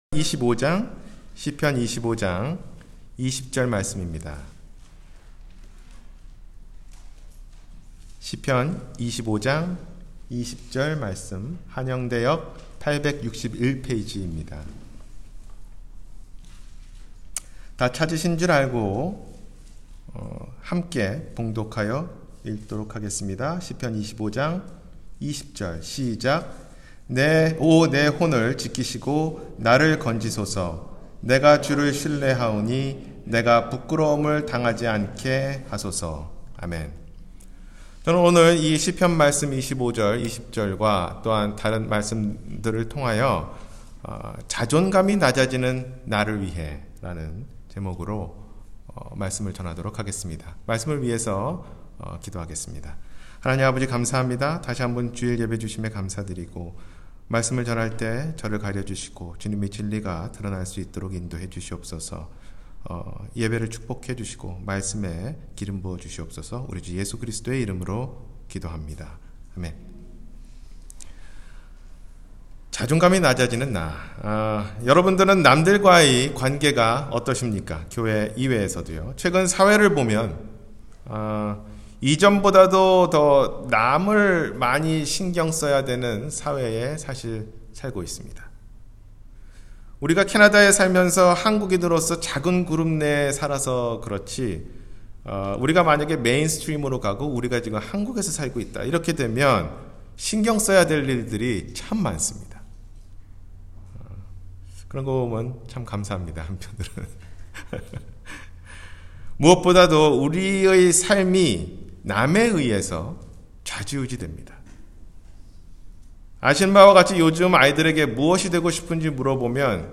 자존감이 낮아지는 나를 위해 – 주일설교